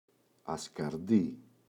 ασκαρντί [aska’rdi]